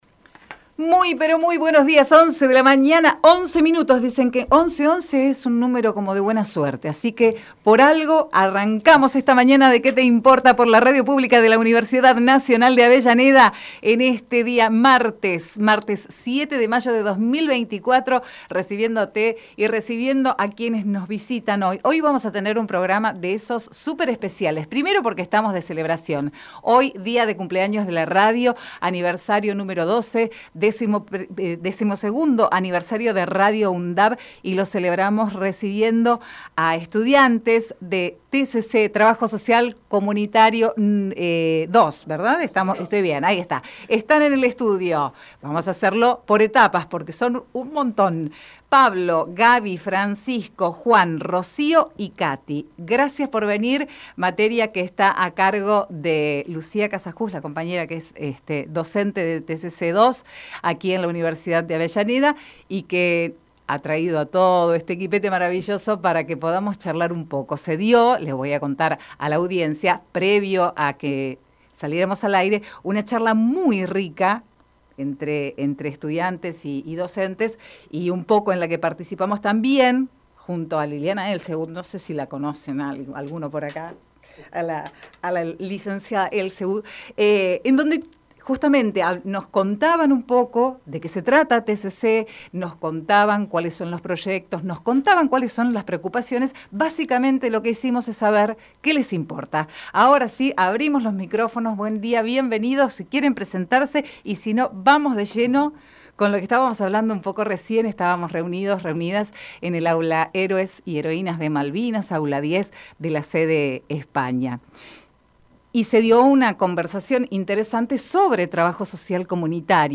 Compartimos entrevista realizada en "Que te Importa" a estudiantes de Trabajo social comunitario 2 "UNDAV".